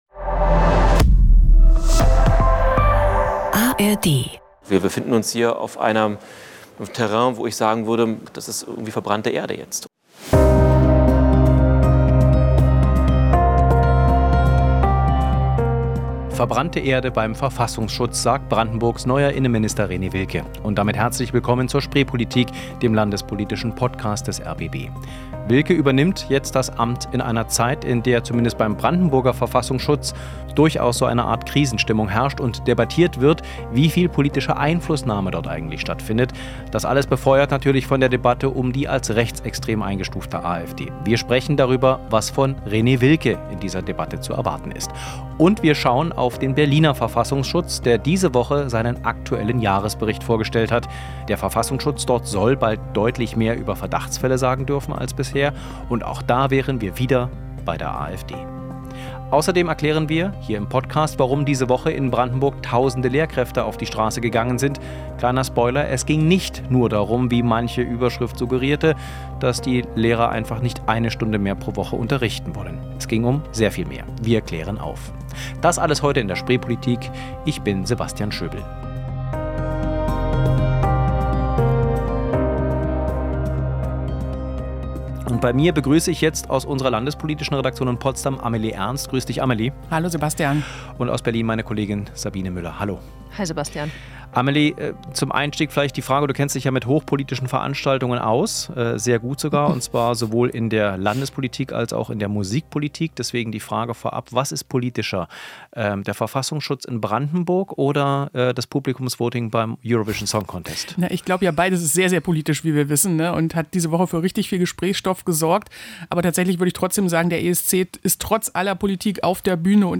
Jede Woche spricht das Team der rbb-Redaktion Landespolitik über die großen, kleinen und besonderen Themen aus Berlin und Brandenburg.